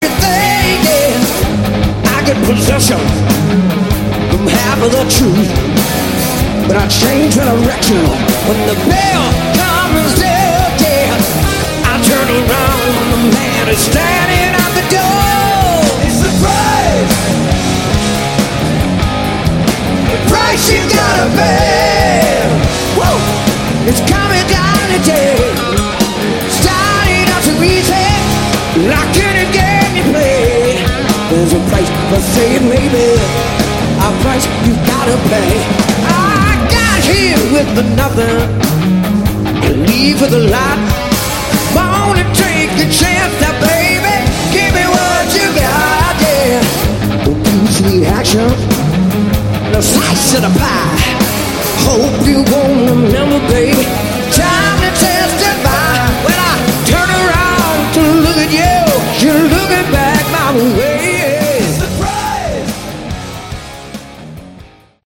Category: Hard Rock
Bass
vocals
Drums
Guitars